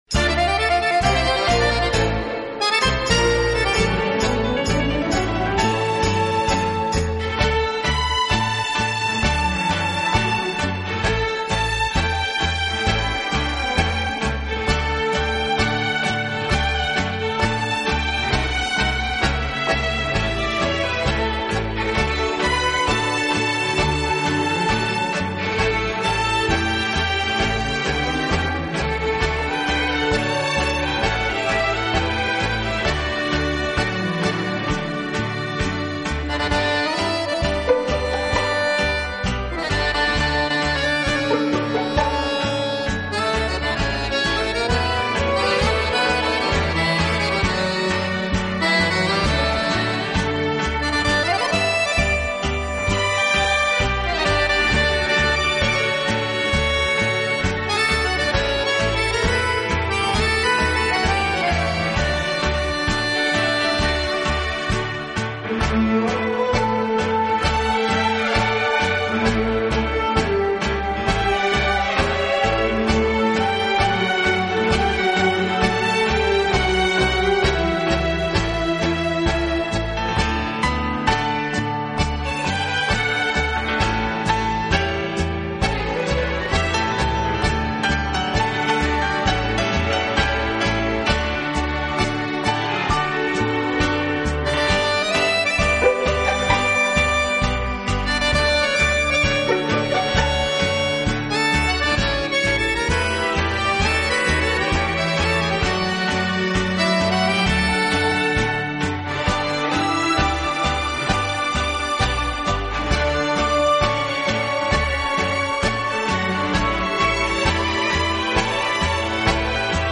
Genre: Latin/Tango
舞曲节奏鲜明，每小节两拍，都是重音。